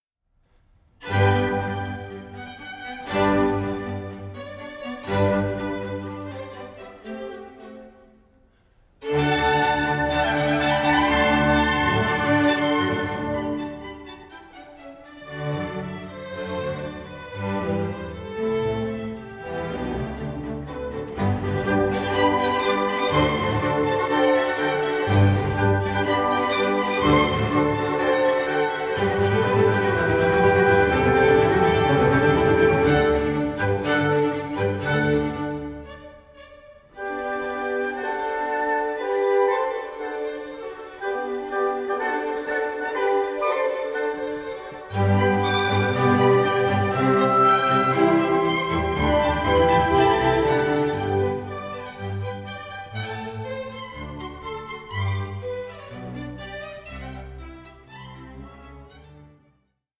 Concerto for Violin & Orchestra No. 3 in G Major